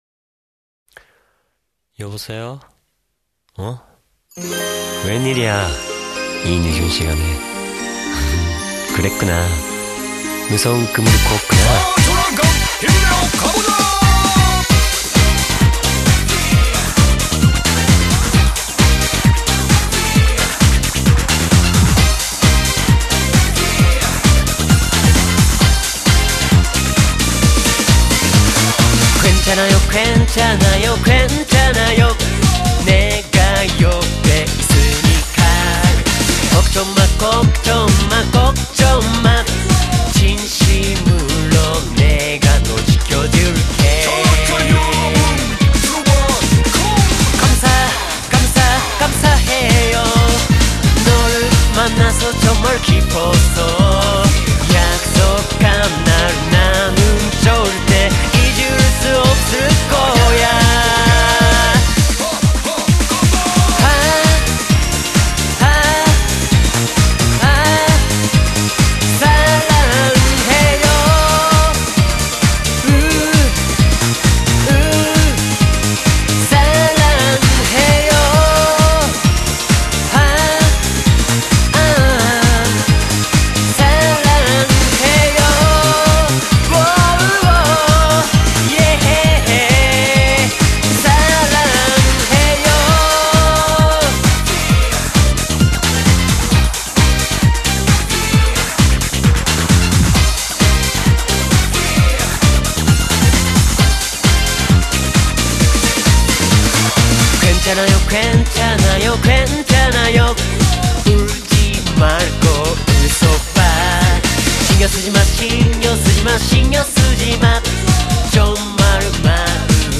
월드컵 기간 일본가수가 한국어로 부른 노래의 싱글 판을 발매해 화제를 일으키고 있다．